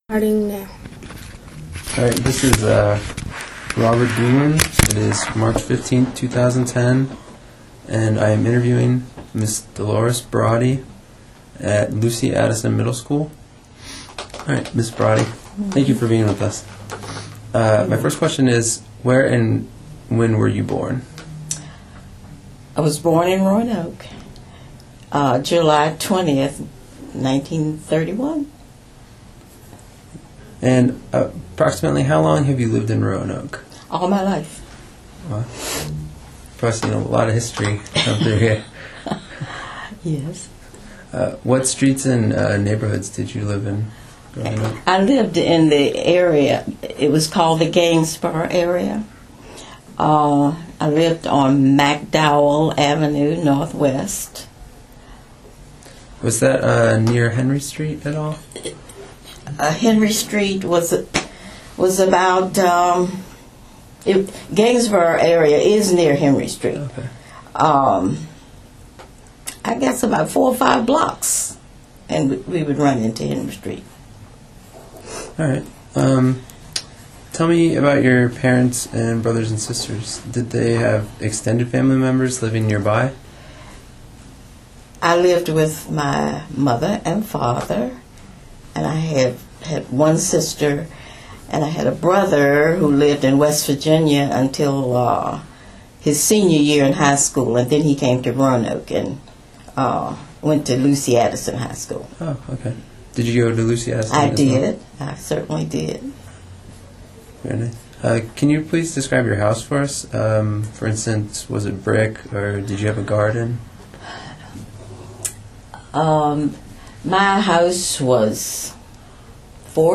Neighborhood History Interview
Location: Lucy Addison Middle School